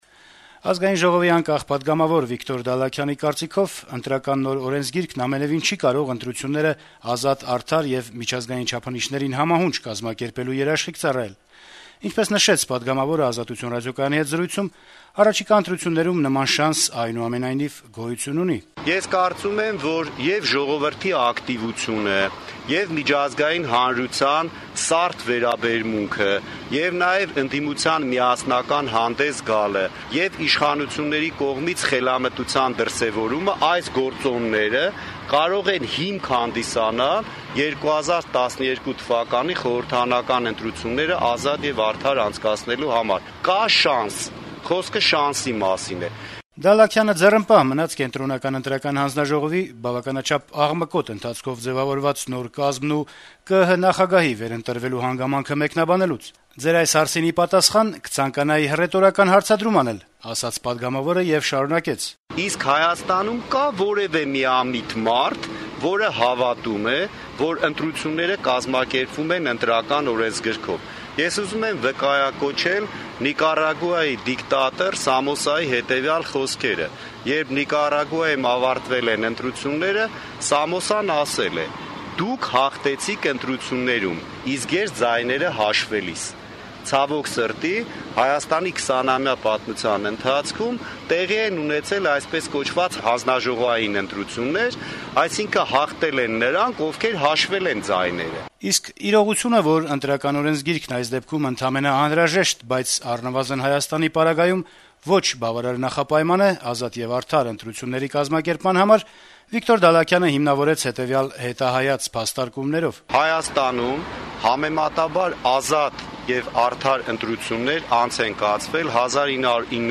«Ազատություն» ռադիոկայանին տված հարցազրույցում այսպիսի համոզմունք հայտնեց Վիկտոր Դալլաքյանը: